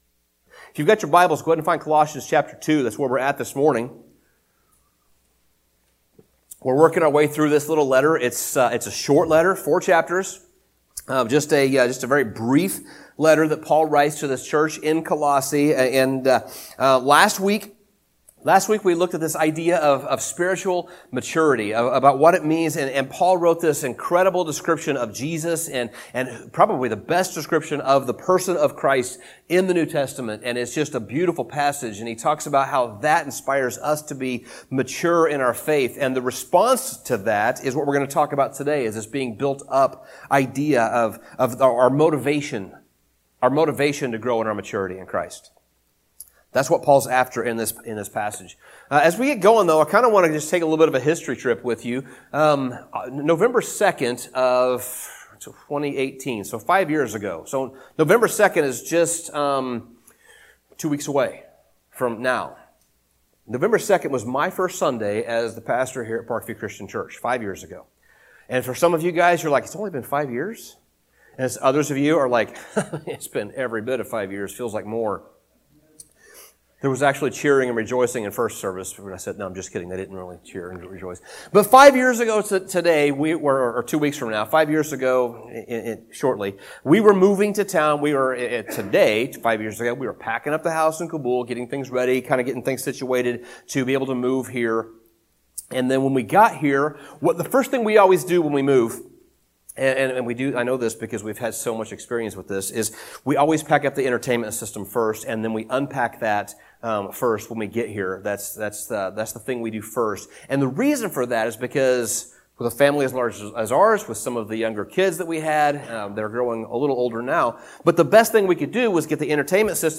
Sermon Summary What does maturity in Christ look like? Paul describes that in detail as chapter 2 begins.